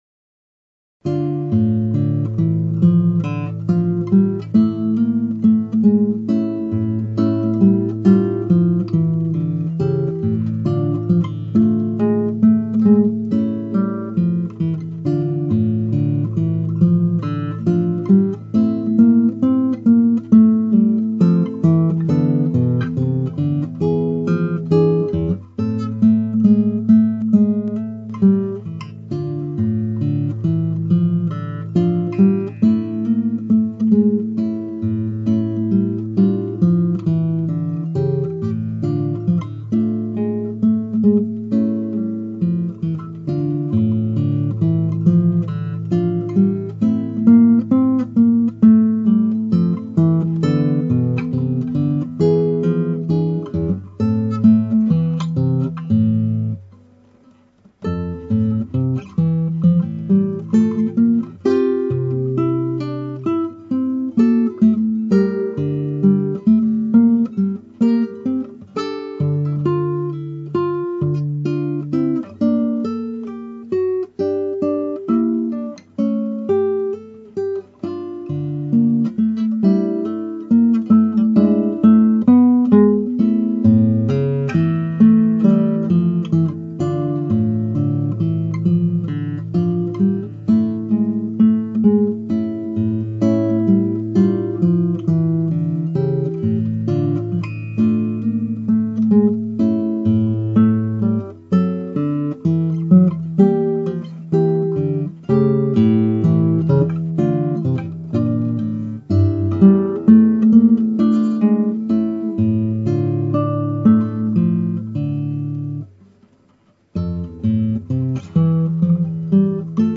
(アマチュアのクラシックギター演奏です [Guitar amatuer play] )
歌うように流れる低音声部の上に乗った高音声部の旋律をきれいに分離して弾く練習曲です。
今回アップした録音はレッスンで言えば、とりあえず通して弾けるようになったと言うレベルです。